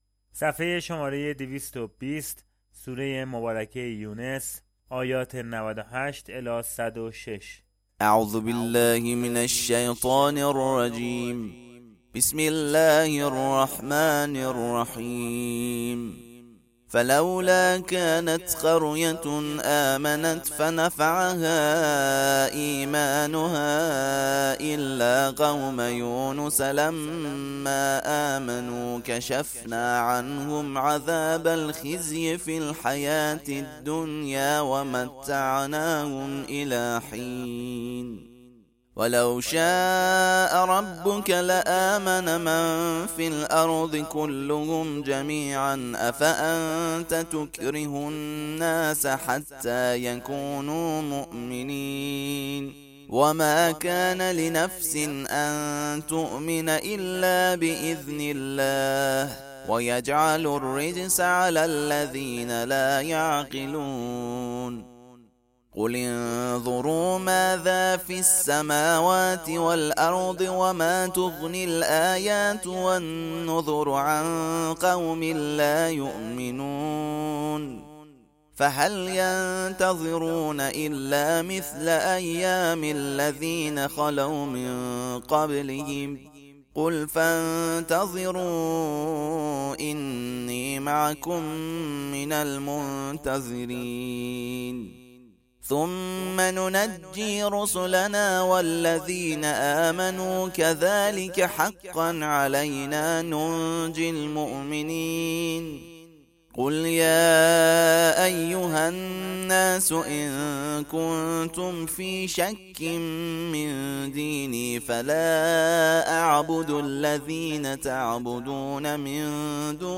ترتیل صفحه ۲۲۰ سوره مبارکه یونس(جزء یازدهم)
ترتیل سوره(یونس)